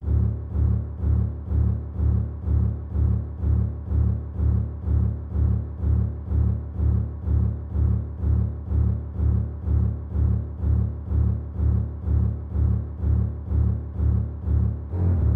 Tag: 125 bpm Cinematic Loops Strings Loops 2.58 MB wav Key : D